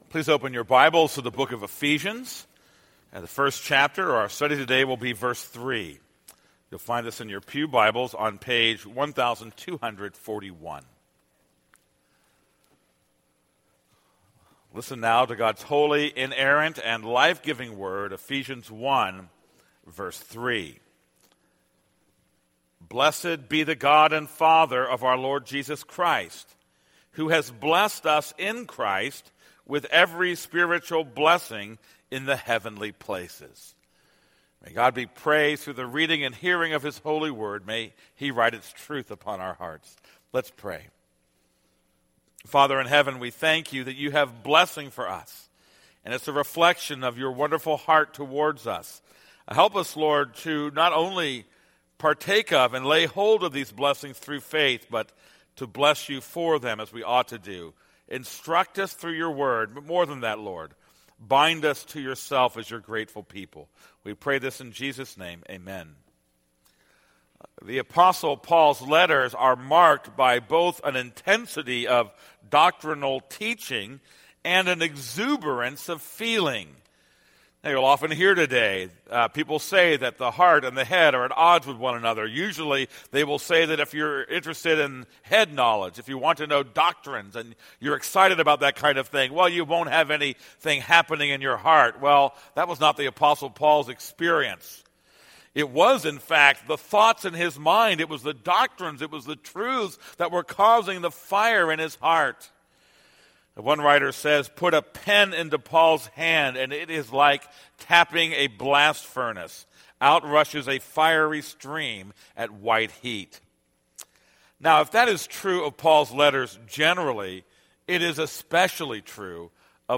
This is a sermon on Ephesians 1:3.